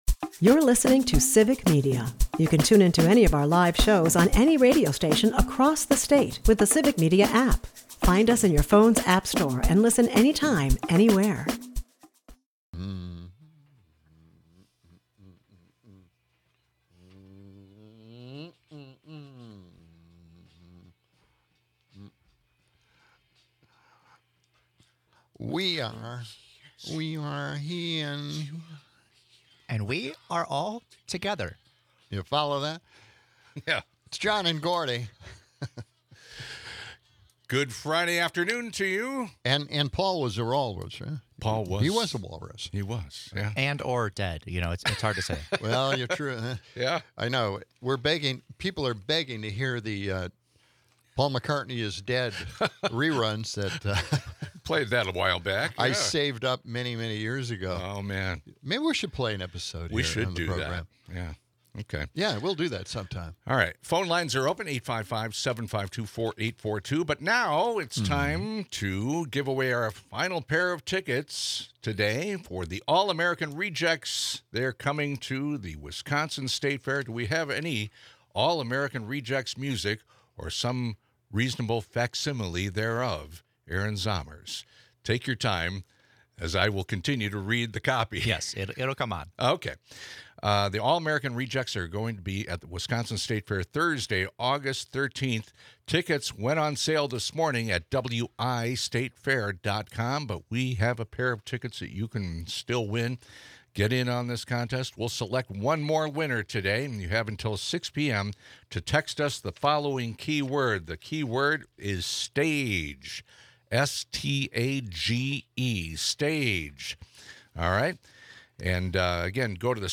take a few calls with thoughts on the Minneapolis ICE situation.